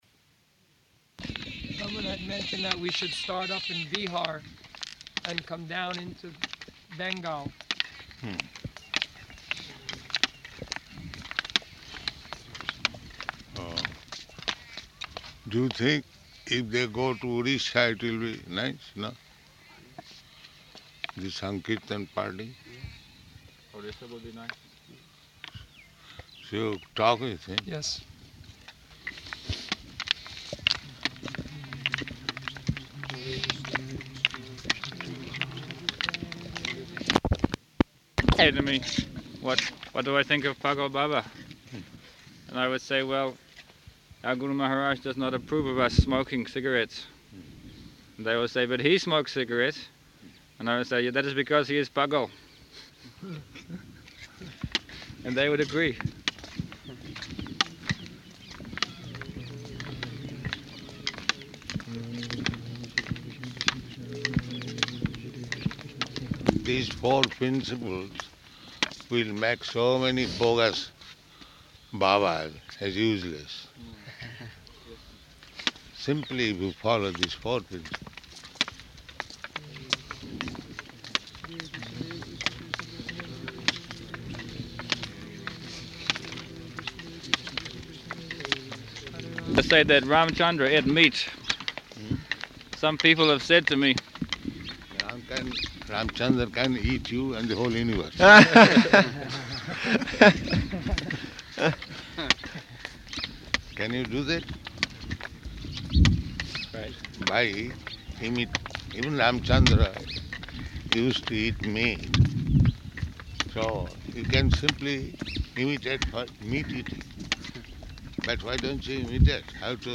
-- Type: Walk Dated: December 4th 1975 Location: Vṛndāvana Audio file